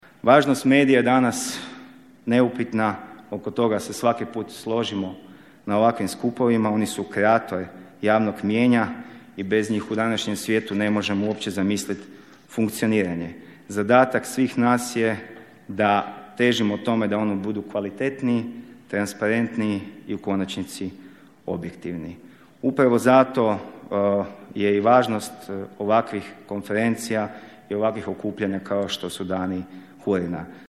Dani HURiN-a održavaju se u Svetom Martinu na Muri u organizaciji Hrvatske udruge radijskih nakladnika, a u partnerstvu Zaklade Konrad Adenauer, HAKOM-a (Hrvatske regulatorne agencije za mrežne djelatnosti) i OIV (Odašiljača i veza).
A da je mjerama u očuvanju radnih mjesta u medijskom sektoru pomogla i Vlada istaknuo je tajnik u Ministarstvu kulture i medija Krešimir Partl.